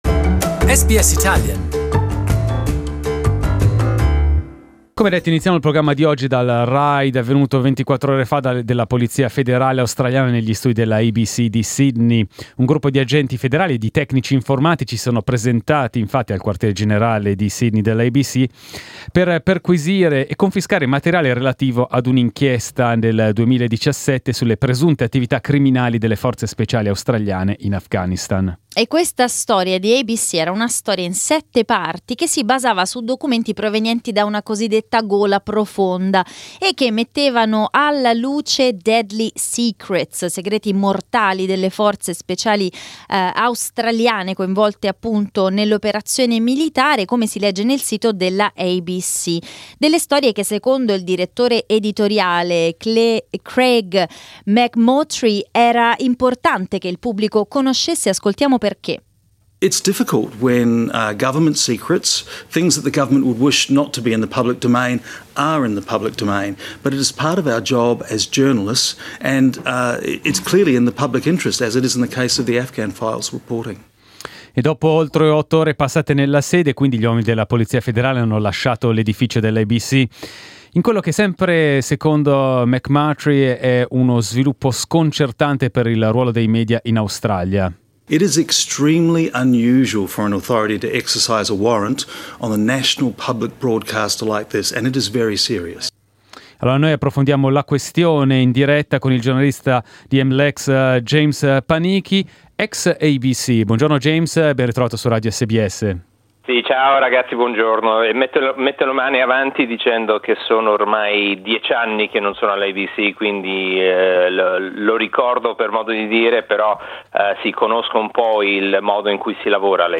We spoke to Australian MLex journalist